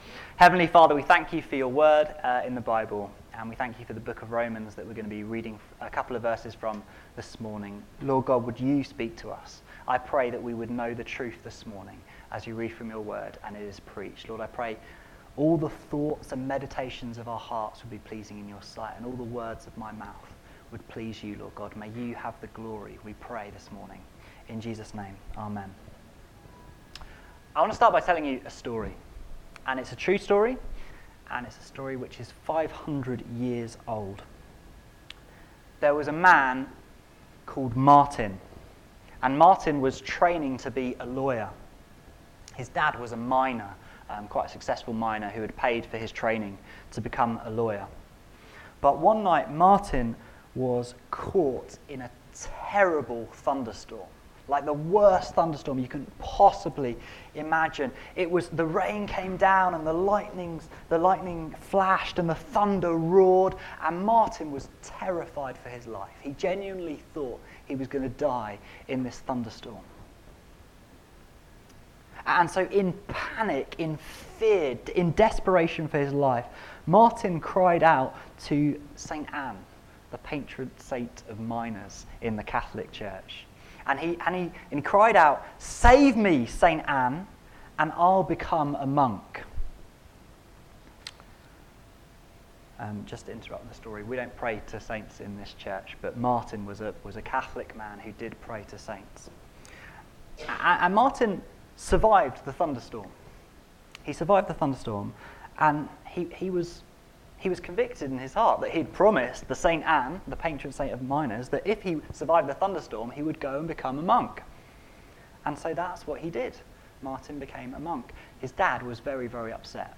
How does God save us? How does a monk named Martin find comfort from Romans 1:16-17? Find out in this evangelistic sermon from those verses.